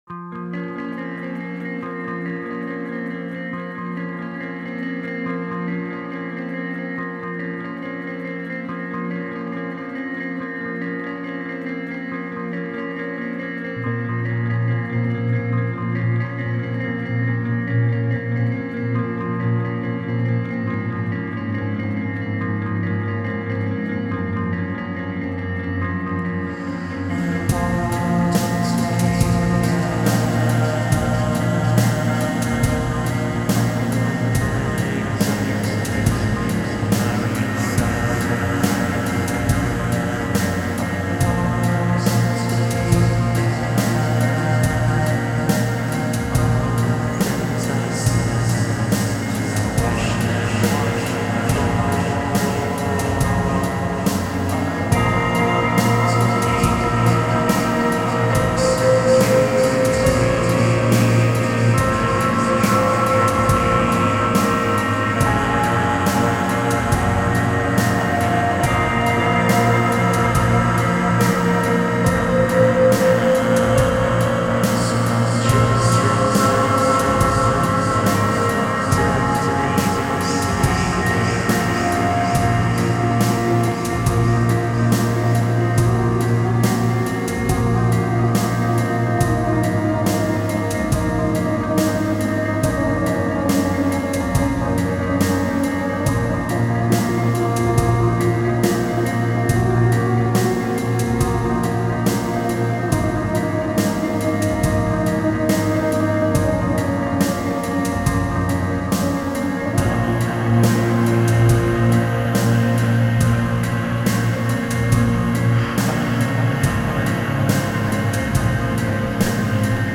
*фоновая композиция –
музыка атмосферная